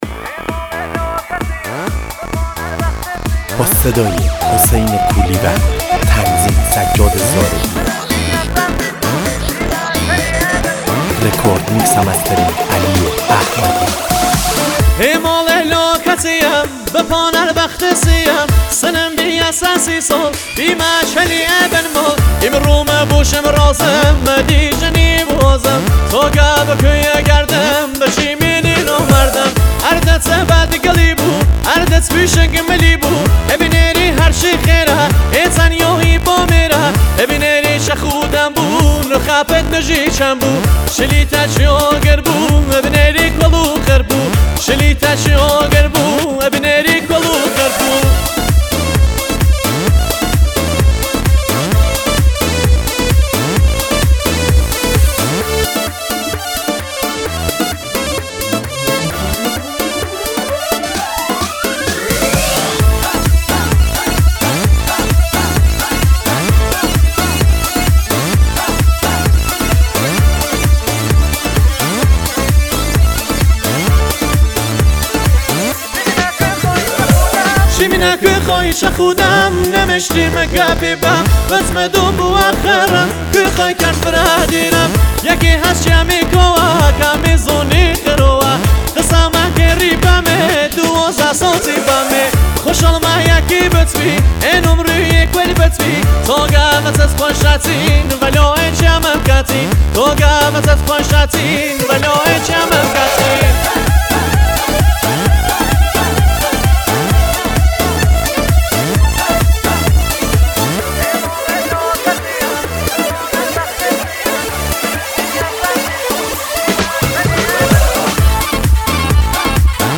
آهنگ کردی شاد آهنگ لری
آهنگ طنز
یک قطعه شاد و مفرح است
آهنگ کردی شاد